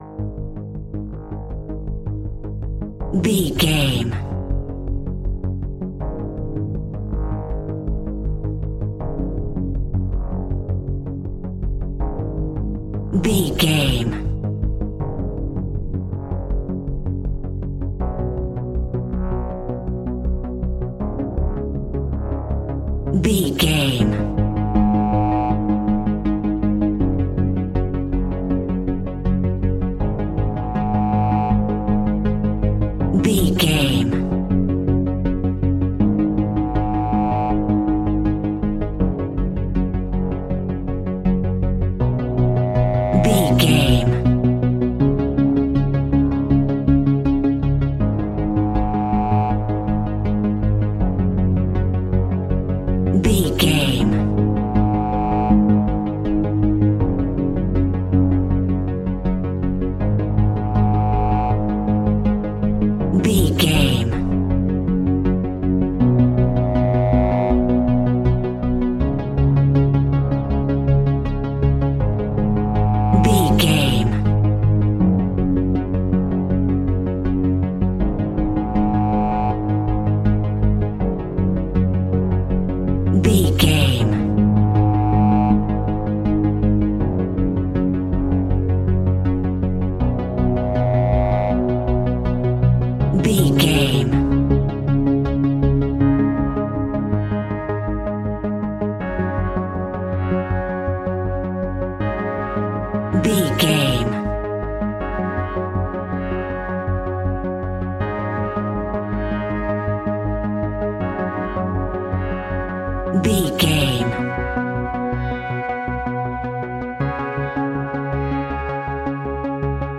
royalty free music
Aeolian/Minor
A♭
ominous
dark
haunting
eerie
synthesizer
drum machine
instrumentals
horror music